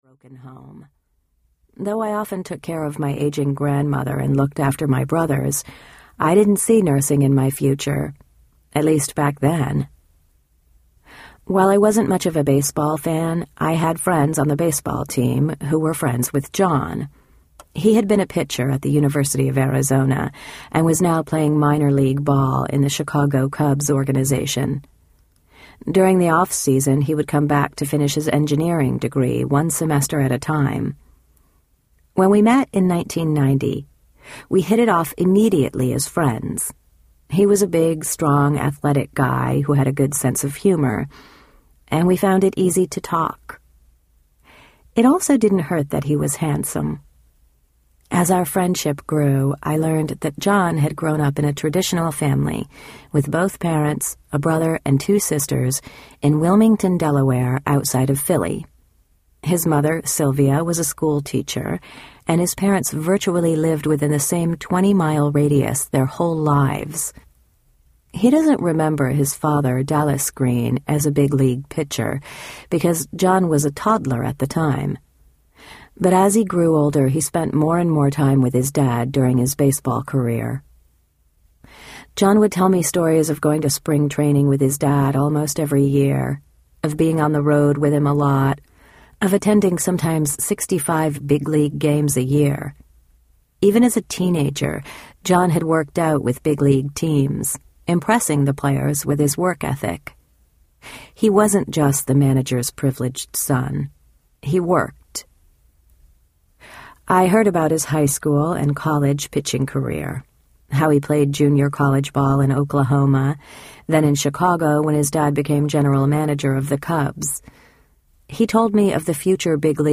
As Good As She Imagined Audiobook
7 Hrs. – Unabridged